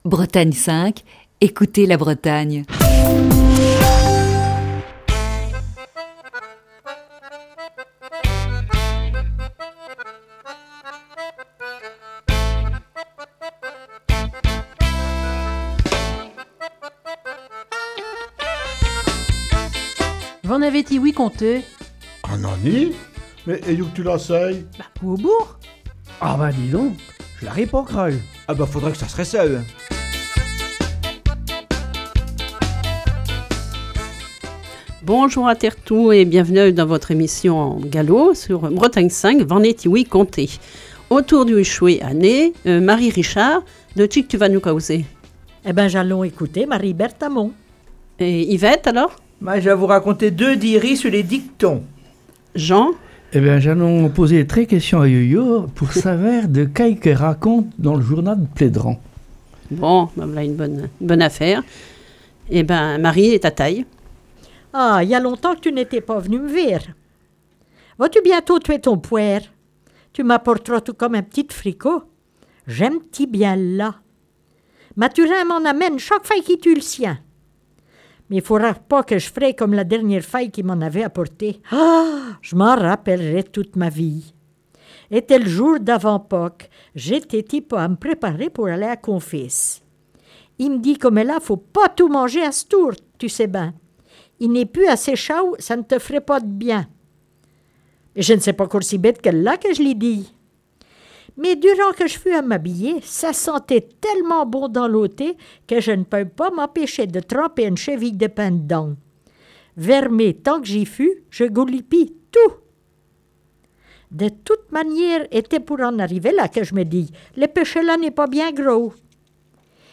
La troupe de V'en avez ti-ouï conté ? nous propose une série d'émissions basée sur "La Nuit de la Lecture", qui se déroulait le 18 janvier dernier à la Bibliothèque de Lamballe.